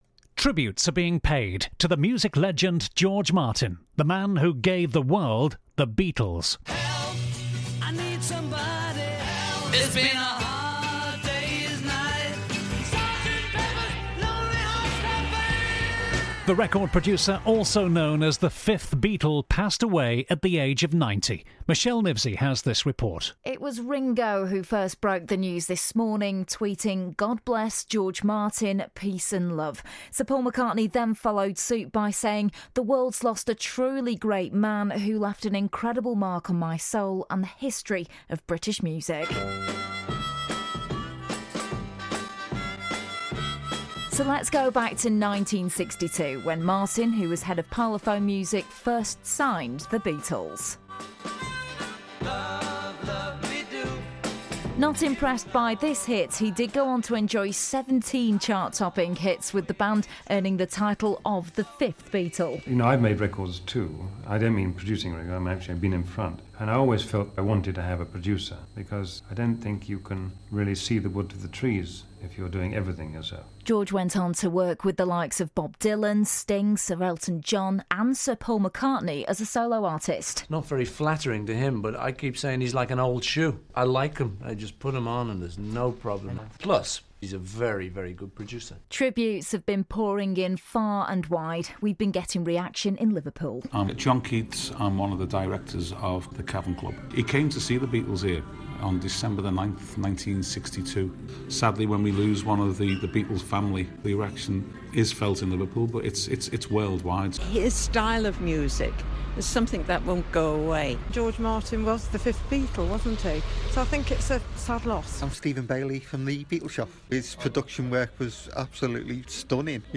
Hear our latest extended bulletin this lunchtime.